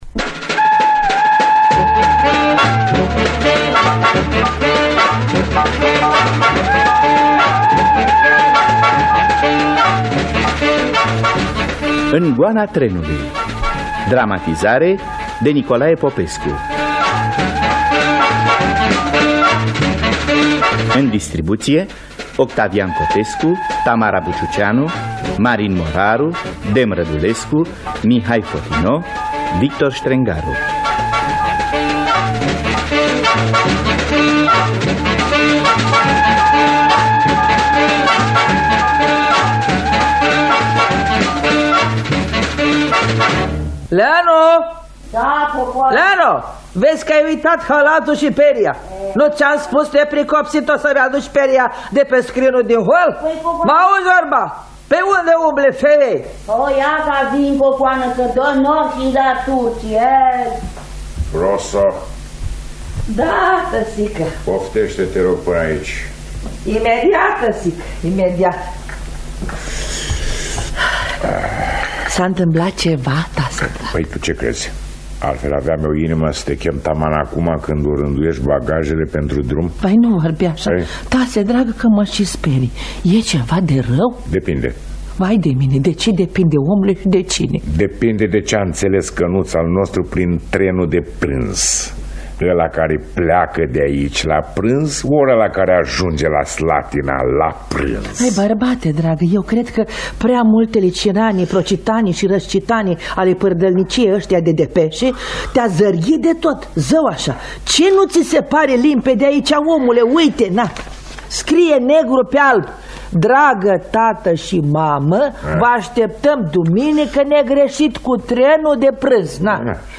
“În goana trenului” de I.A. Bassarabescu – Teatru Radiofonic Online